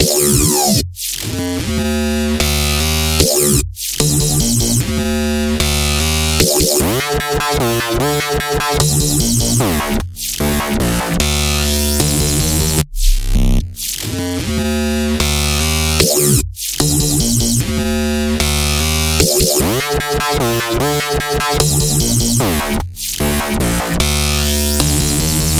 标签： 150 bpm Dubstep Loops Bass Synth Loops 4.31 MB wav Key : G
声道立体声